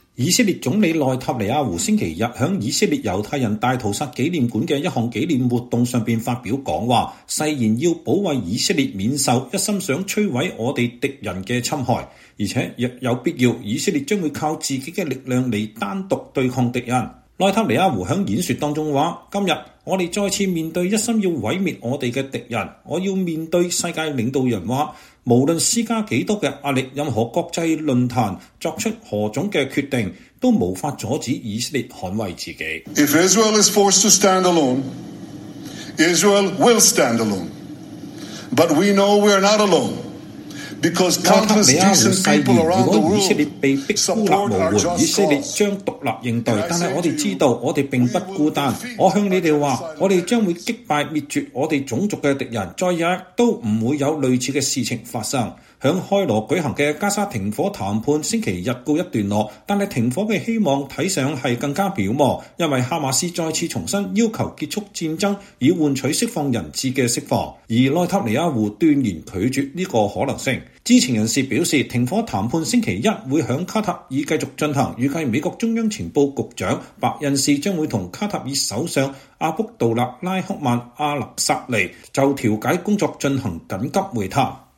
以色列總理本雅明·內塔尼亞胡（Benjamin Netanyahu）星期日（5月5日）在以色列猶太人大屠殺紀念館的一項紀念活動上發表講話，誓言要保衛以色列免受“一心想摧毀我們的敵人”的侵害，且若有必要，以色列將靠自己的力量來單獨對抗敵人。